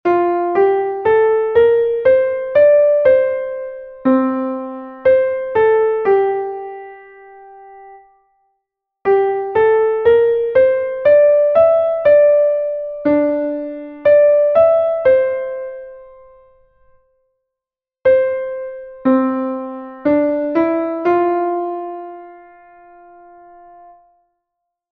Exercise 1 8ve interval practice